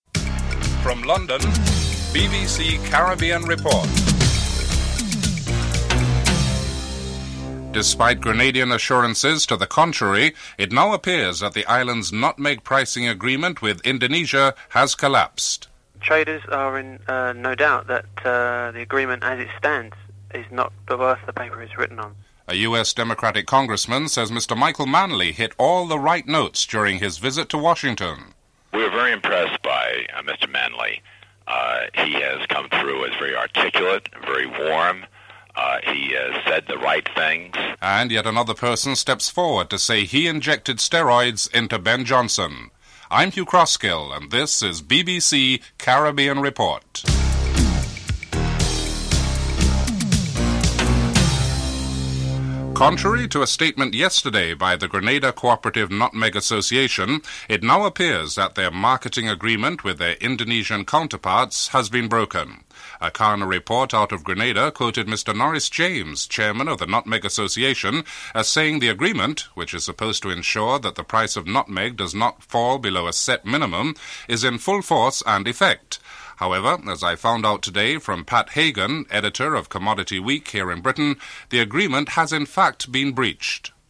1. Headlines (00:00-00:51)
3. Interview with Frank Wareni, Democratic Congressman on Michael Manley's visit to Washington (05:43-08:45)
4. Financial News (08:46-10:08)